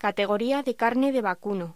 Locución: Categoría de carne de vacuno
voz